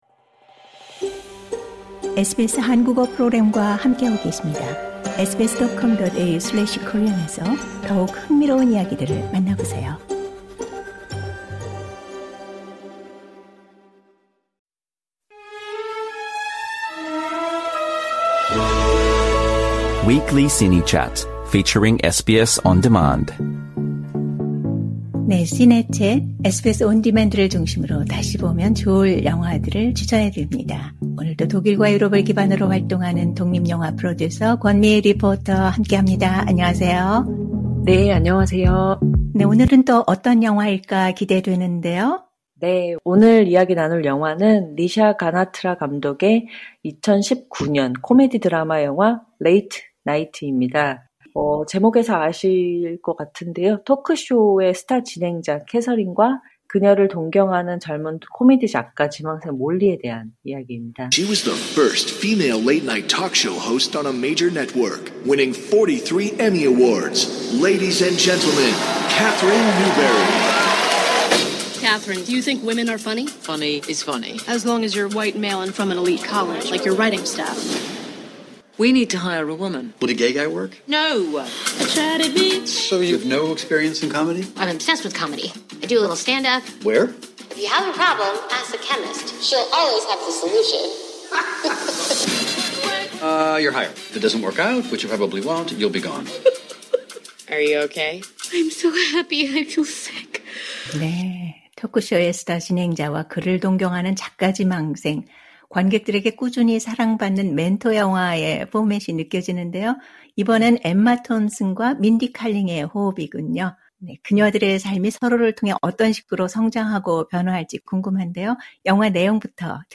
Trailer Audio Clip